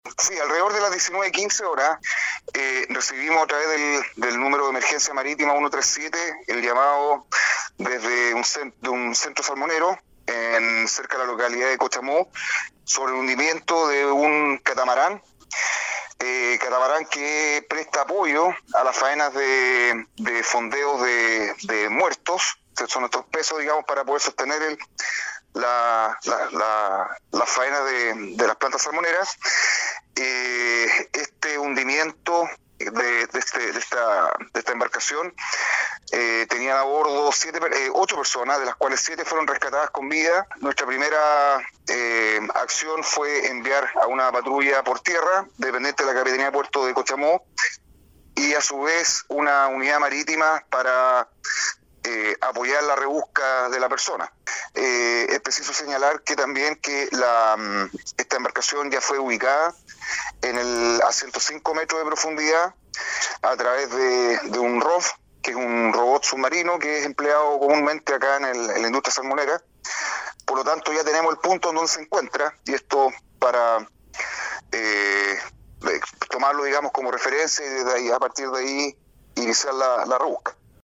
En el momento del accidente, la embarcación “Doña Ester” transportaba a trabajadores de la empresa Camanchaca, la que  habría estado realizado maniobras de fondeo, de acuerdo a los antecedentes aportados por la autoridad marítima por intermedio del gobernador marítimo de Puerto Montt, Dinson Baack.
03-GOBERNADOR-MARITIMO-DINSON-BAACK.mp3